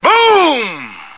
flak_m/sounds/male2/est/M2boom.ogg at fd5b31b2b29cdd8950cf78f0e8ab036fb75330ca